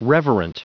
Prononciation du mot reverent en anglais (fichier audio)
Prononciation du mot : reverent